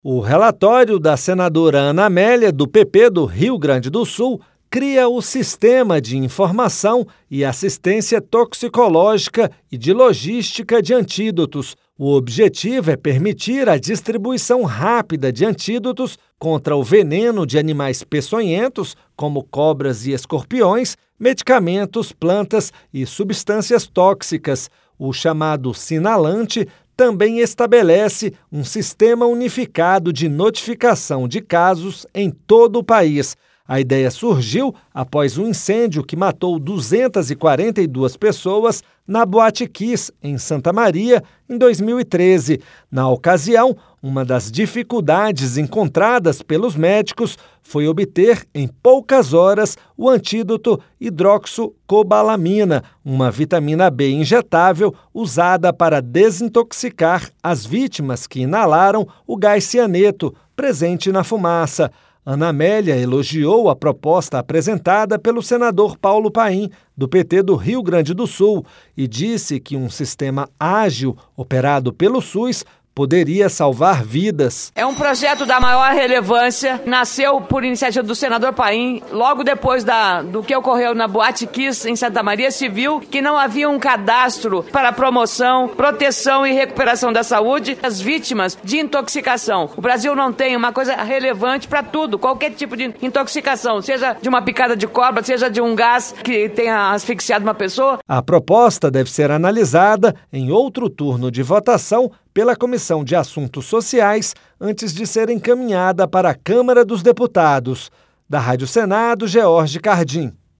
A senadora Ana Amélia (PP-RS) elogiou a proposta apresentada pelo senador Paulo Paim (PT-RS) e disse que um sistema ágil operado pelo SUS poderia salvar vidas. Ouça os detalhes no áudio do repórter da Rádio Senado